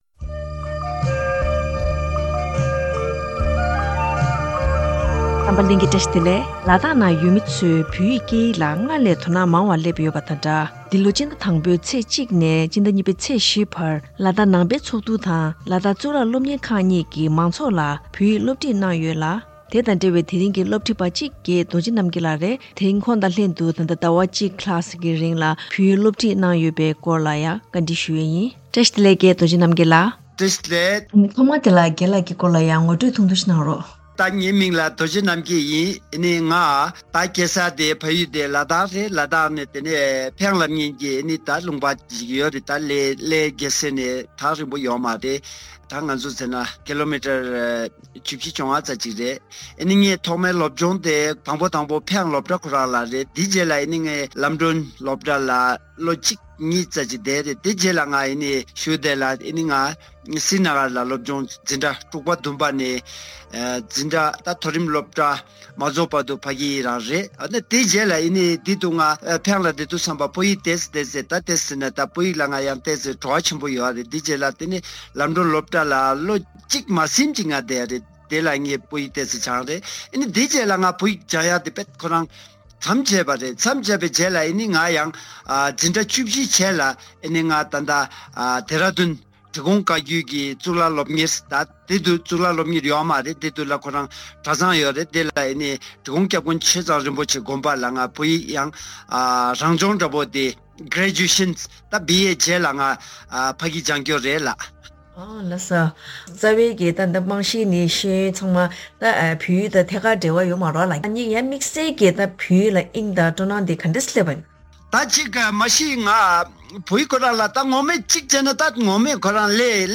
གནས་འདྲིའི་ལེ་ཚན